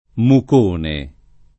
Mucone [ muk 1 ne ]